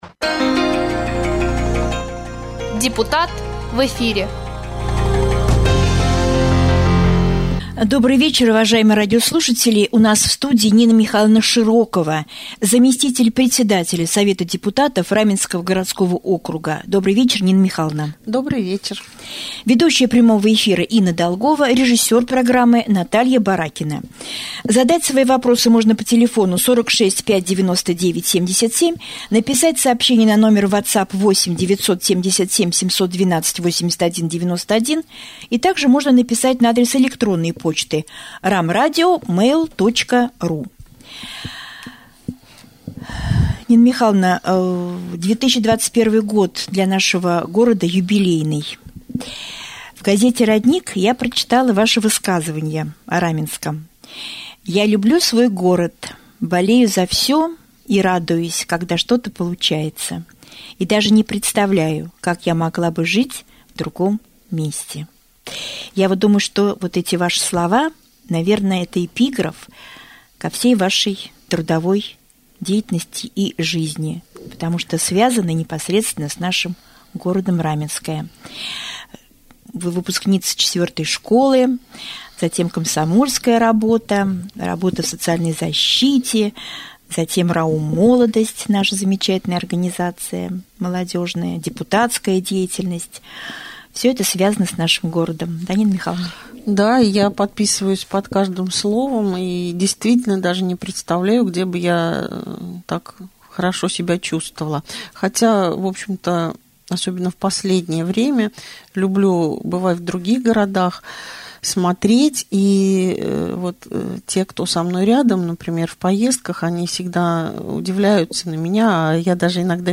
На прямом эфире Раменского радио заместитель председателя Совета депутатов Раменского г.о. Нина Михайловна Широкова обсудила темы: